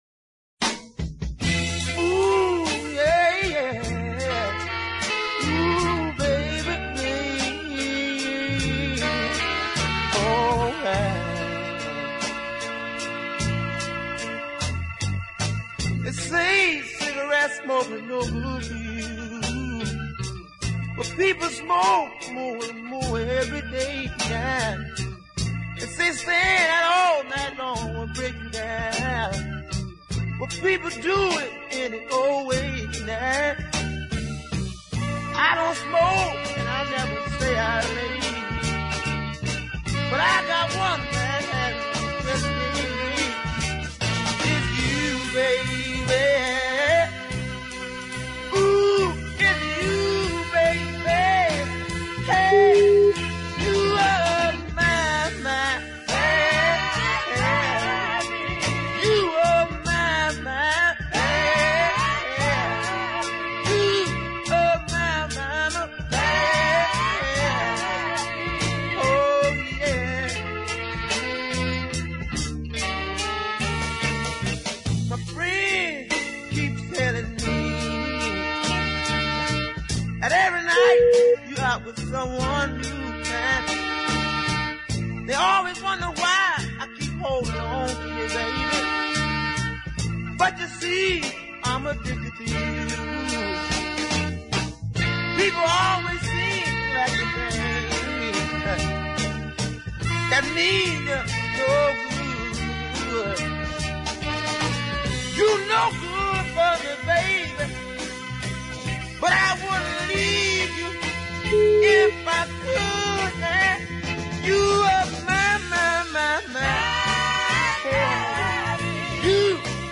You gotta love the falsetto howl in the run out groove.